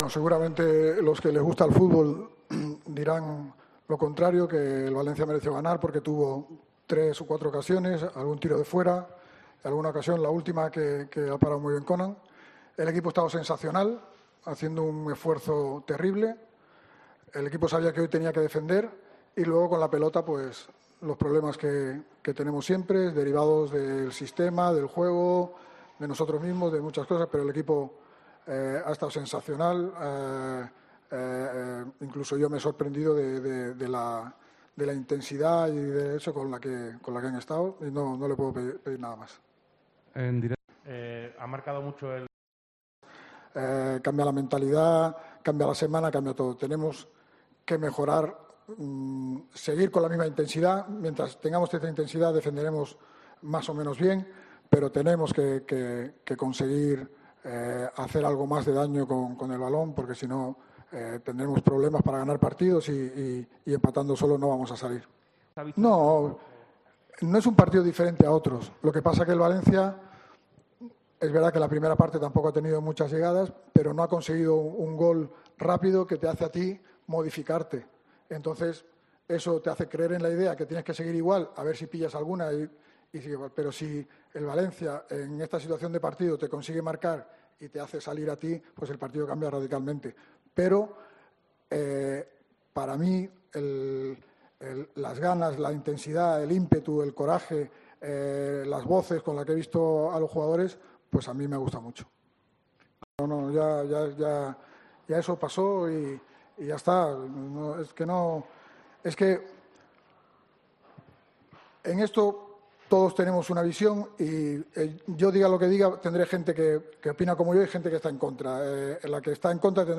Álvaro Cervera tras el Cádiz 0-0 Valencia